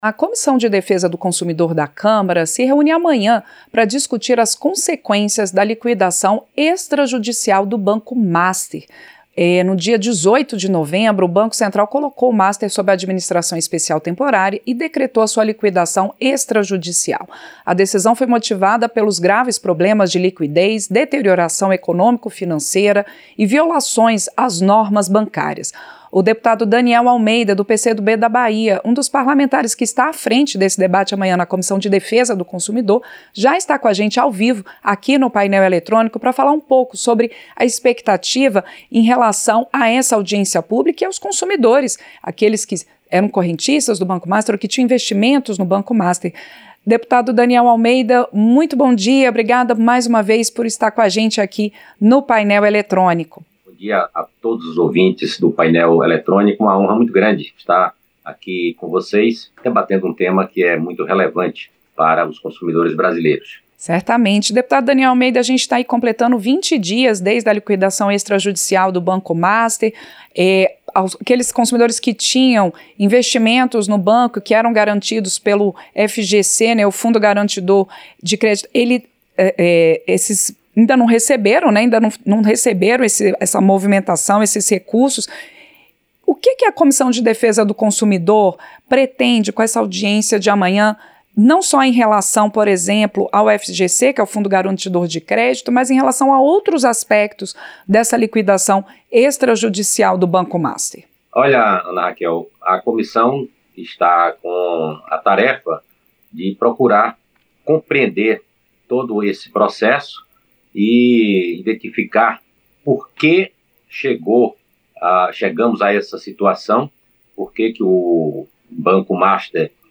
Entrevista - Dep. Daniel Almeida (PCdoB-BA)